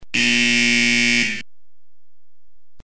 Buzzer